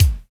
SZ KICK 03.wav